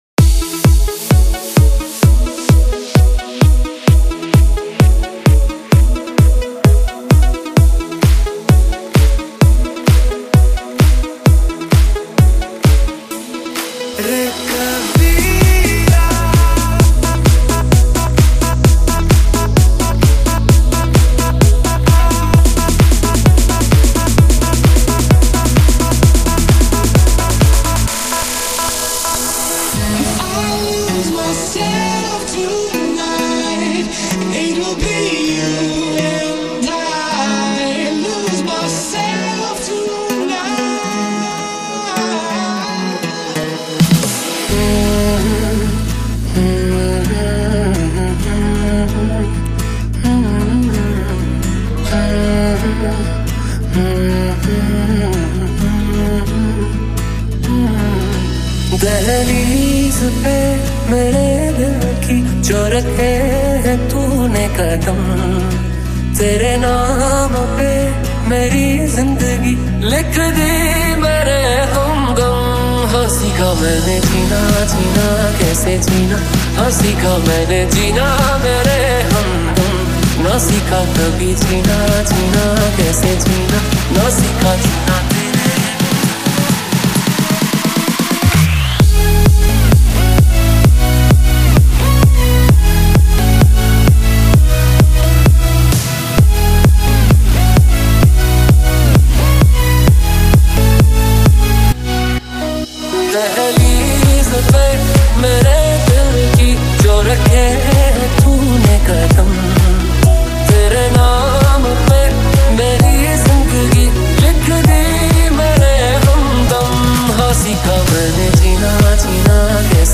Bollywood DJ Remix
Bollywood DJ Remix Songs